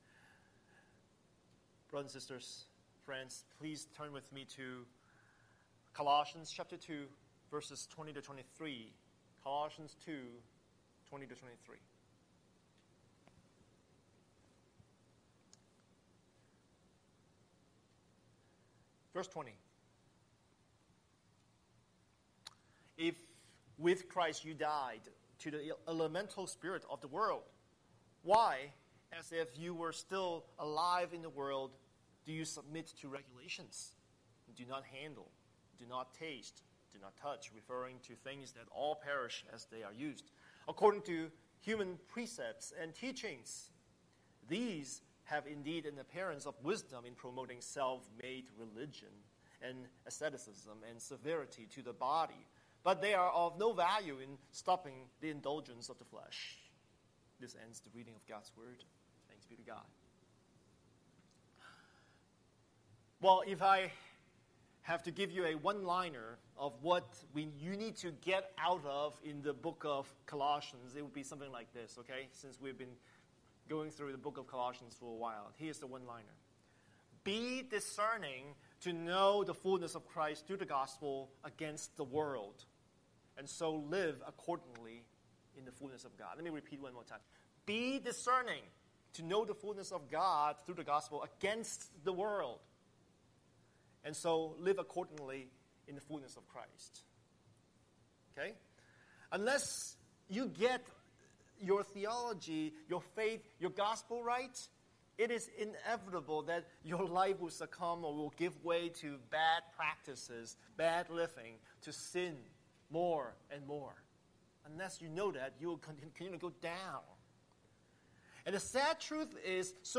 Scripture: Colossians 2:20-23 Series: Sunday Sermon